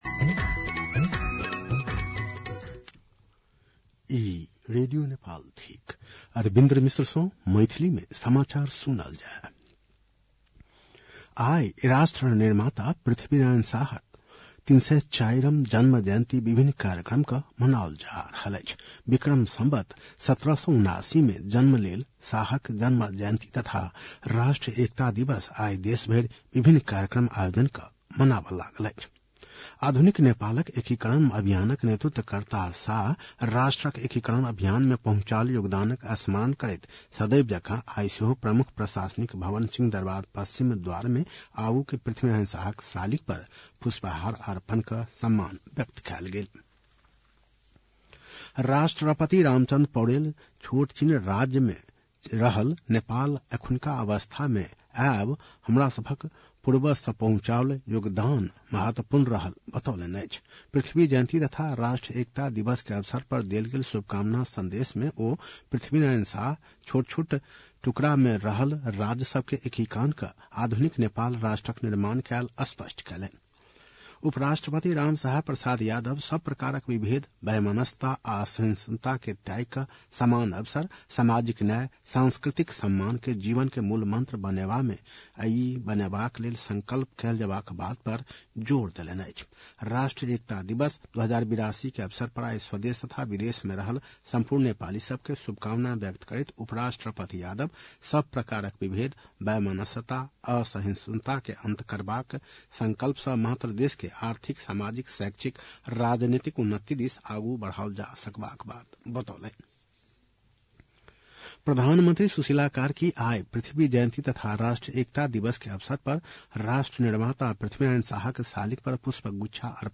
मैथिली भाषामा समाचार : २७ पुष , २०८२
Maithali-news-9-27.mp3